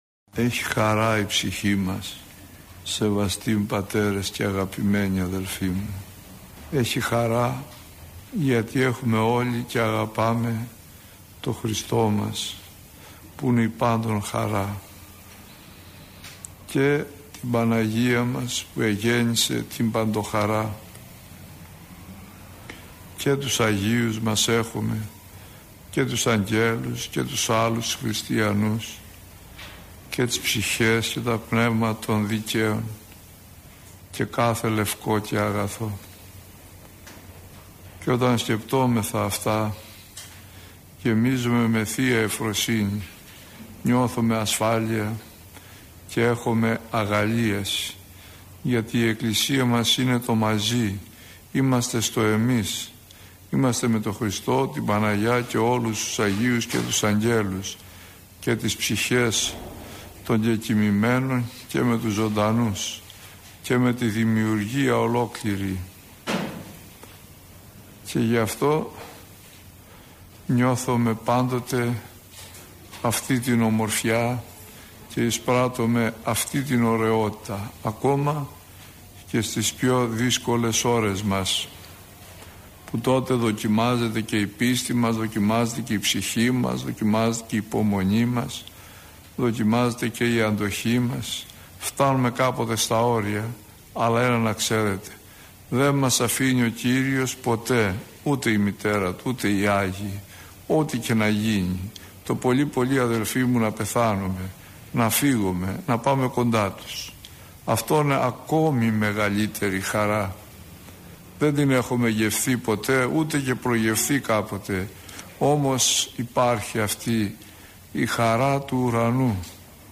ηχογραφημένη ομιλία